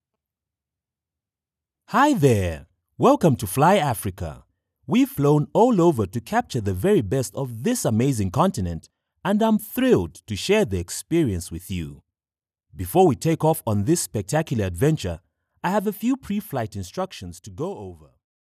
Animation
Middle Aged
Fly Africa (Animation - Young Adult African Accent).mp3